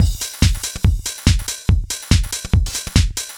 Index of /musicradar/uk-garage-samples/142bpm Lines n Loops/Beats
GA_BeatA142-01.wav